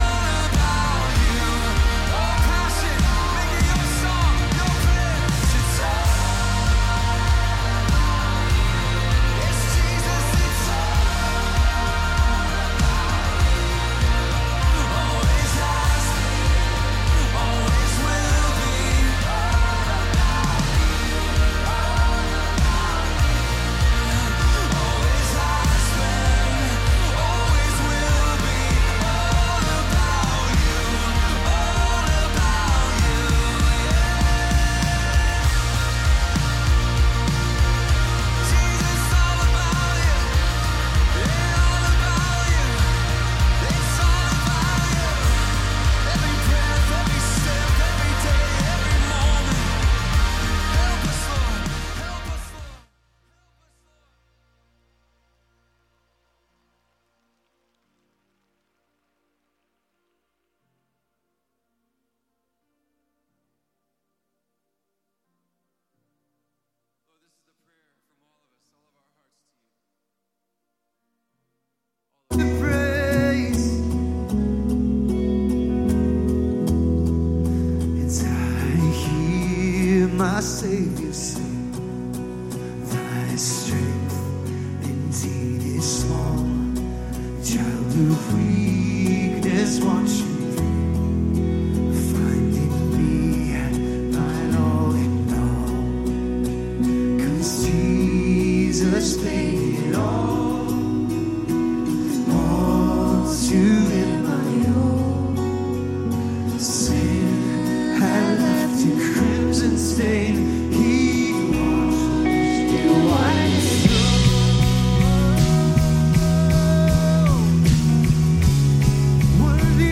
Calvary Knoxville Sunday AM Live!